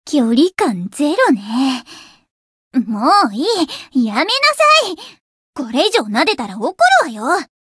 灵魂潮汐-敖绫-互动-不耐烦的反馈1.ogg